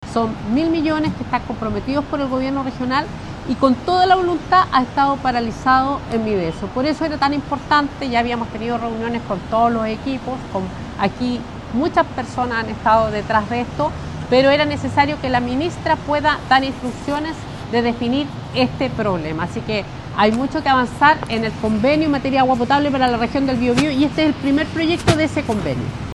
La diputada Joanna Pérez, quien gestionó la reunión con la ministra, expresó la necesidad que se agilicen las recomendaciones sociales para estos proyectos en la región del Biobío.